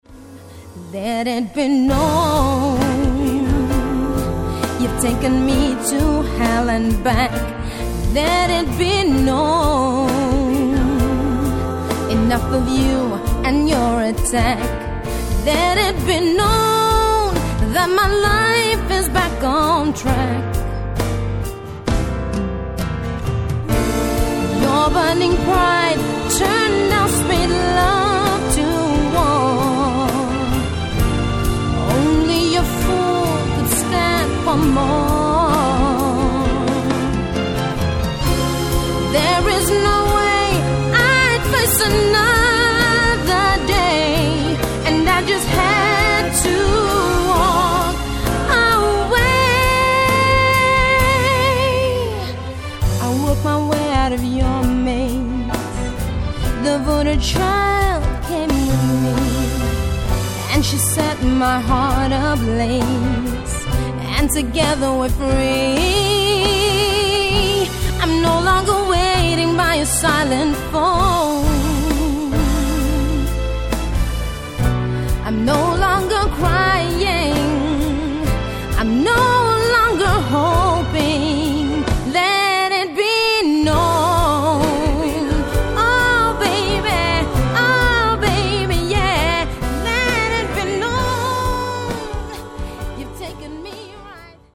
These songs are pre-production demos.